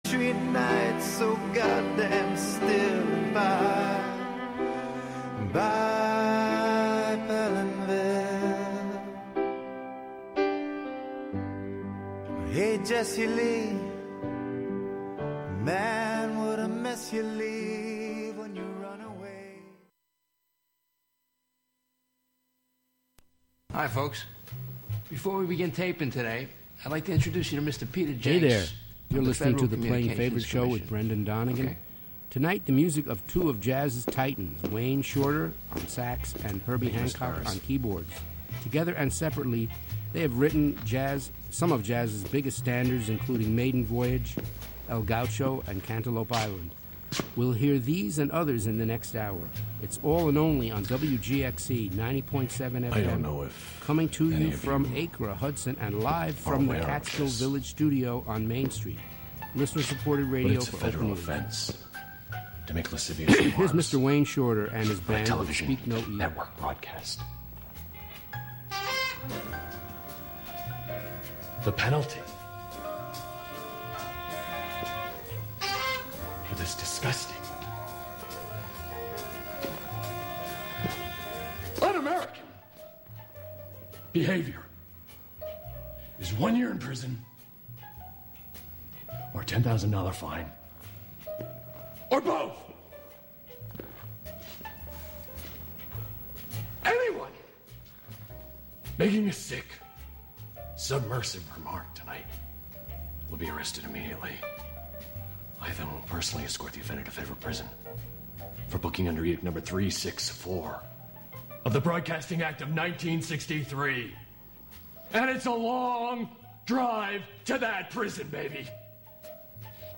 sax
piano